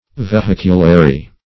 \Ve*hic"u*la*ry\